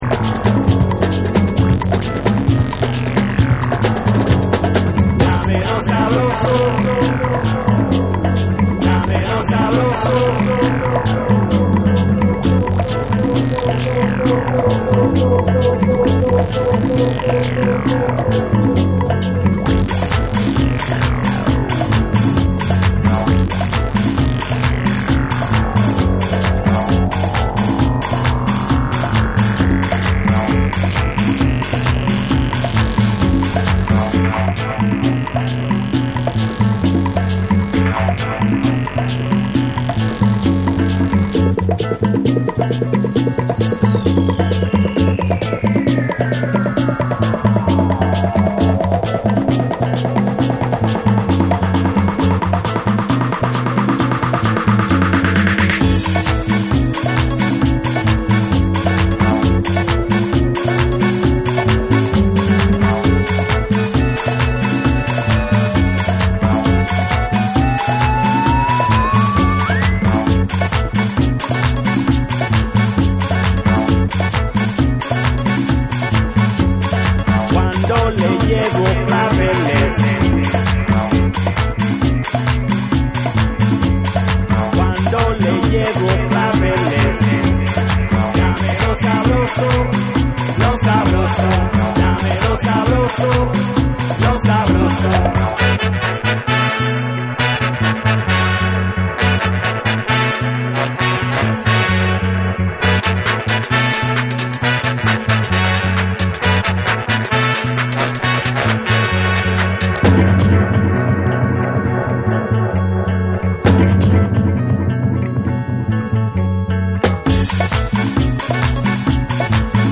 BREAKBEATS / ORGANIC GROOVE / AFROBEAT